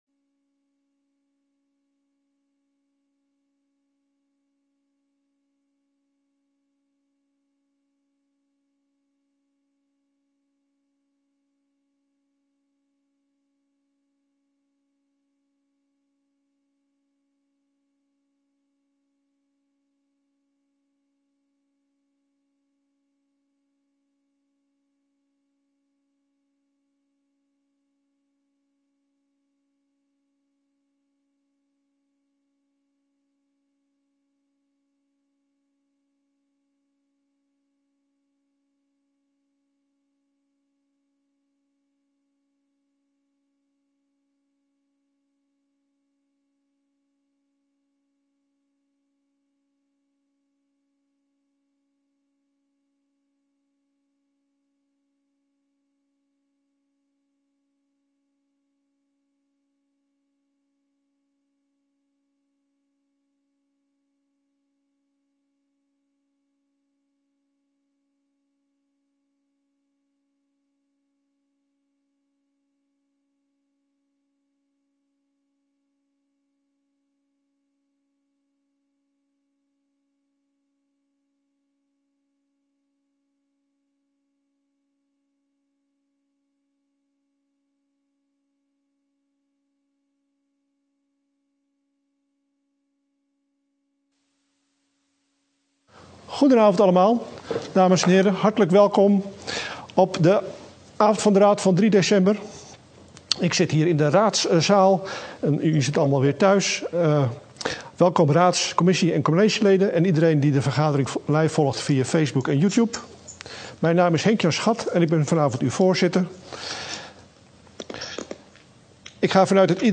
De vergadering wordt digitaal gehouden gezien de aangescherpte maatregelen.